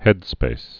(hĕdspās)